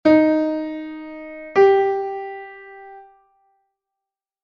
Mib Sol.3 M (audio/mpeg)
3ª mayor